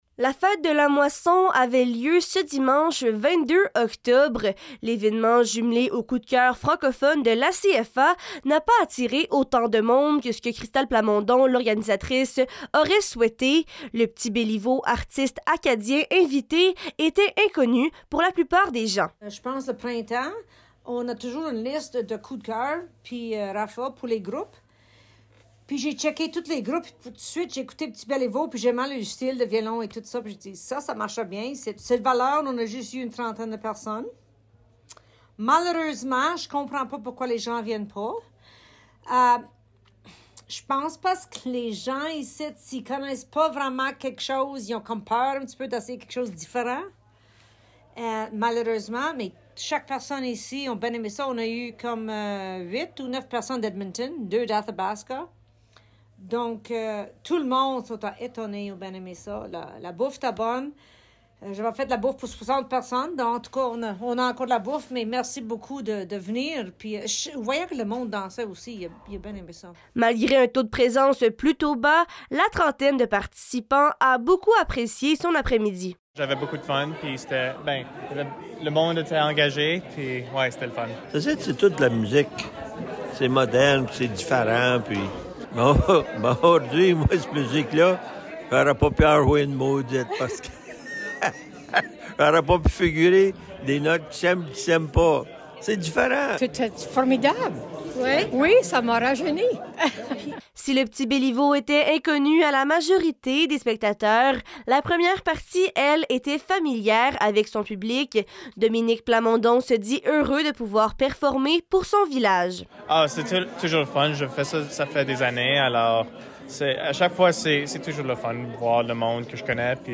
Fete-Moisson-Vox-Pop-Reportage-1.mp3